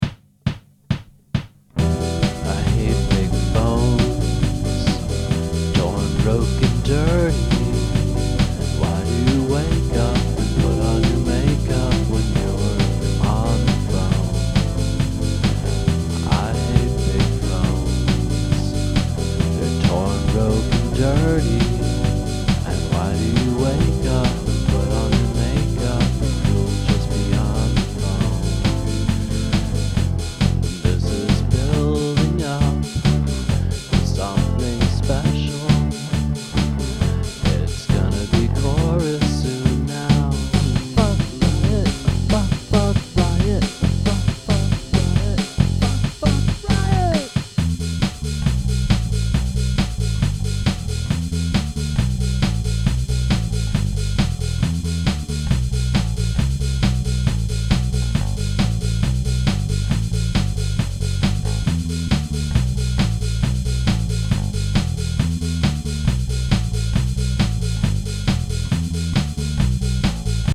Oh no, it's a disco beat!
It's at 135 bpm right now - do you think a drop to around 120-125 would be better?